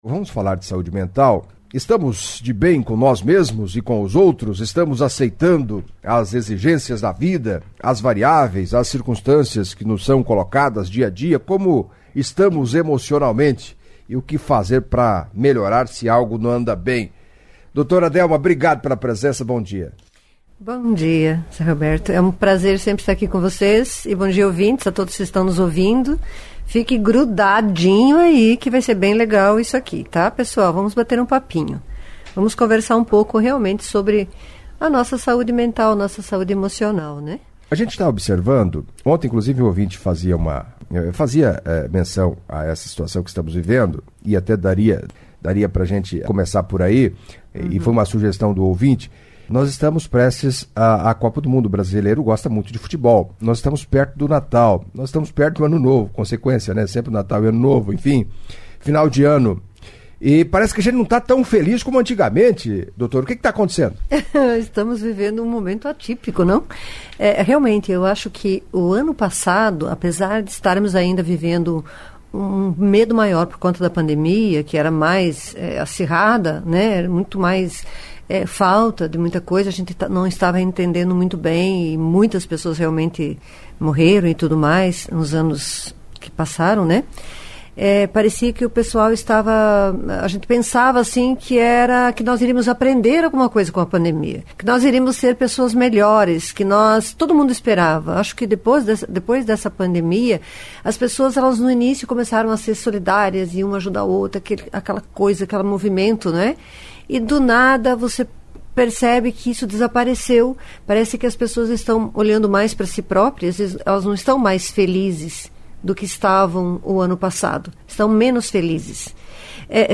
Em entrevista à CBN Cascavel nesta quinta-feira